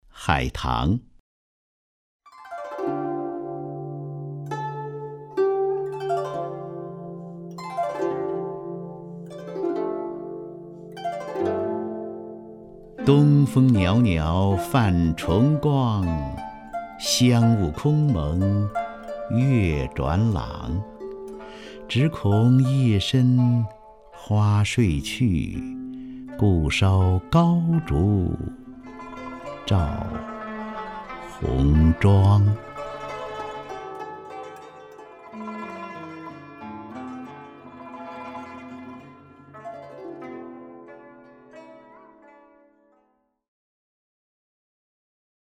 张家声朗诵：《海棠》(（北宋）苏轼) (右击另存下载) 东风袅袅泛崇光， 香雾空蒙月转廊。